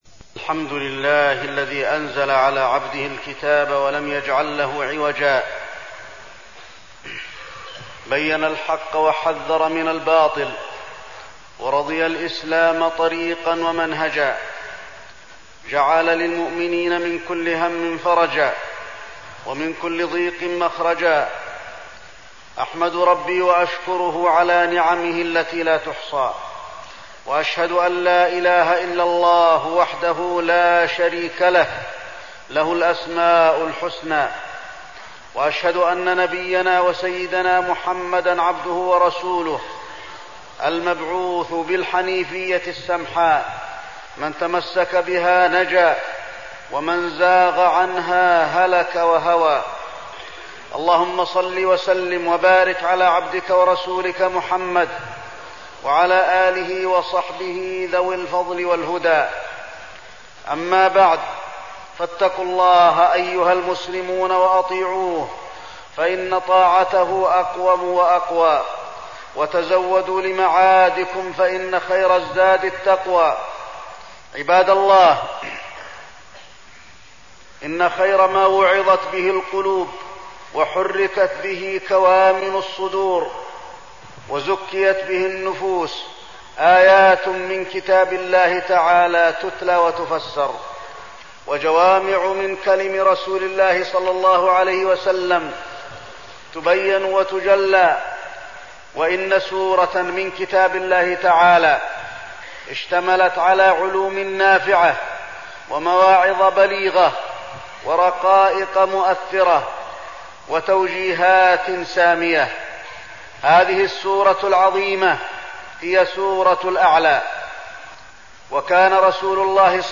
تاريخ النشر ٢٠ جمادى الآخرة ١٤١٧ هـ المكان: المسجد النبوي الشيخ: فضيلة الشيخ د. علي بن عبدالرحمن الحذيفي فضيلة الشيخ د. علي بن عبدالرحمن الحذيفي تفسير سورة الأعلى